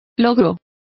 Complete with pronunciation of the translation of accomplishment.